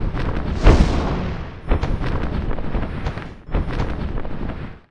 monster2 / fire_dragon / attack1_2.wav
attack1_2.wav